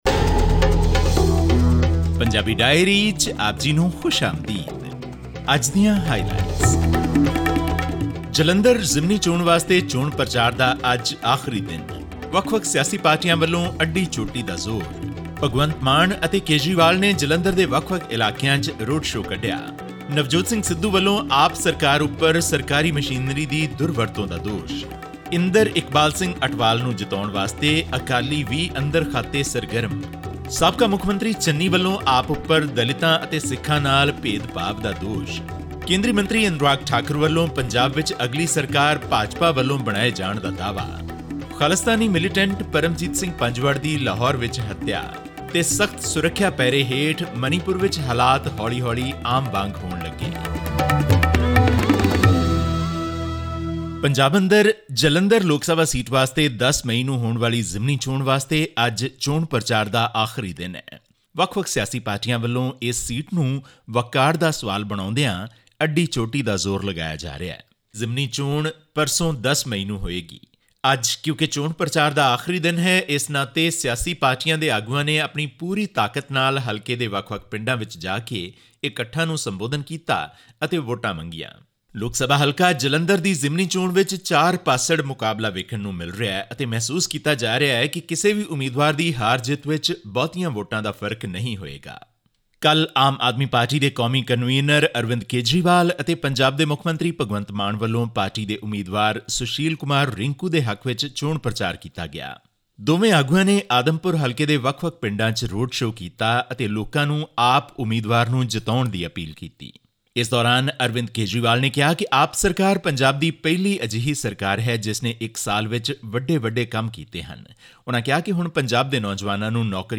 ਮਨੀਪੁਰ ਸਰਕਾਰ ਵੱਲੋਂ ਪੇਸ਼ ਹੋਏ ਸਾਲਿਸਿਟਰ ਜਨਰਲ ਆਫ਼ ਇੰਡੀਆ (ਐਸ ਜੀ ਆਈ) ਤੁਸ਼ਾਰ ਮਹਿਤਾ ਨੇ ਭਾਰਤ ਦੀ ਸੁਪਰੀਮ ਕੋਰਟ ਨੂੰ ਸੂਚਿਤ ਕੀਤਾ ਕਿ ਰਾਜ ਵਿੱਚ ਸਥਿਤੀ ਨੂੰ ਕਾਬੂ ਵਿੱਚ ਲਿਆਉਣ ਲਈ ਸੁਰੱਖਿਆ ਬਲਾਂ ਦੀਆਂ 100 ਤੋਂ ਵੀ ਵੱਧ ਟੁਕੜੀਆਂ ਜੁਟੀਆਂ ਹੋਈਆਂ ਹਨ। ਉਨ੍ਹਾਂ ਨੇ ਦੇਸ਼ ਦੀ ਸਰਵ ਉਚ ਅਦਾਲਤ ਨੂੰ ਸੂਚਿਤ ਕੀਤਾ ਕਿ ਰਾਜ ਵਿੱਚ ਪਿਛਲੇ ਦੋ ਦਿਨਾਂ ਵਿੱਚ ਕੋਈ ਵੀ ਅਣਸੁਖਾਵੀਂ ਘਟਨਾ ਨਹੀਂ ਵਾਪਰੀ ਹੈ। ਇਹ ਖ਼ਬਰ ਅਤੇ ਹੋਰ ਵੇਰਵੇ ਜਾਣਨ ਲਈ ਸੁਣੋ ਇਹ ਖਾਸ ਰਿਪੋਰਟ.....